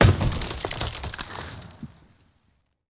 car-tree-wood-impact-02.ogg